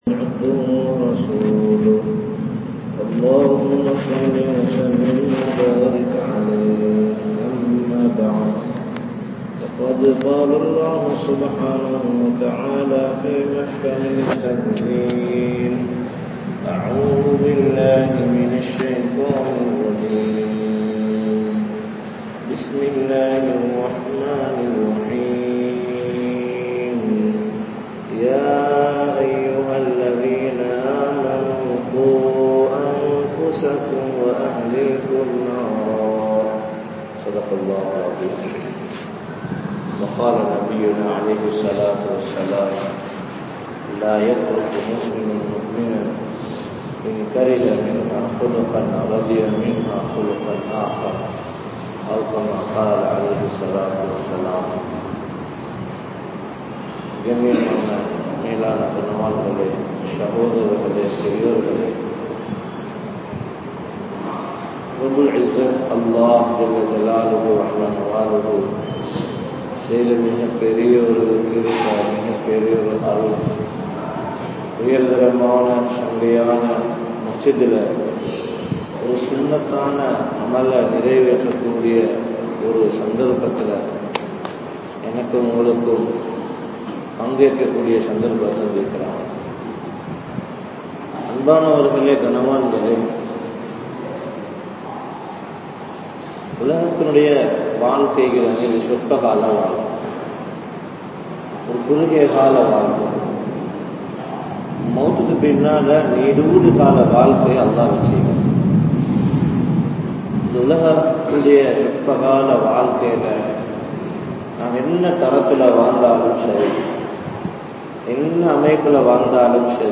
Kanavan Manaivi Anpu (கனவன் மனைவி அன்பு) | Audio Bayans | All Ceylon Muslim Youth Community | Addalaichenai